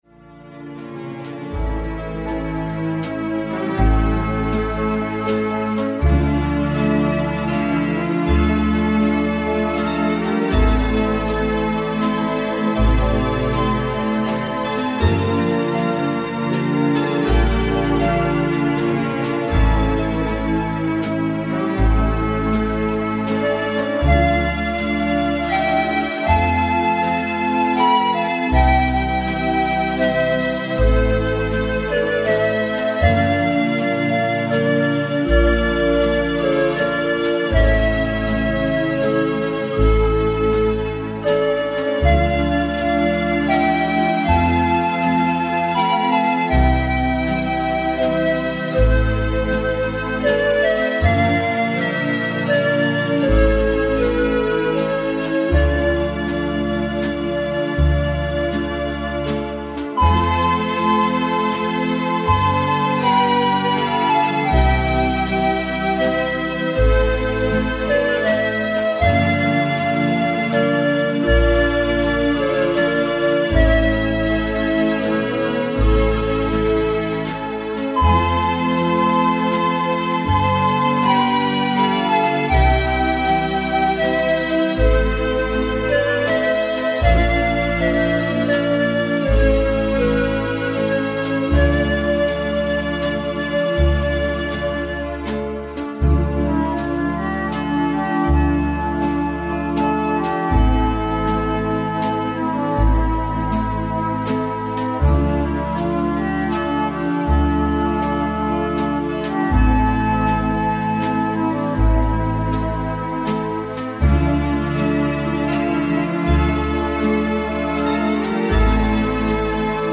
Entspannungsmusik für Wellness, Spa, Massage und Sauna.
Ruhige Instrumentalmusik die entspannt und fröhlich stimmt.
Verbreitung einer ruhigen Atmosphäre und Gelassenheit.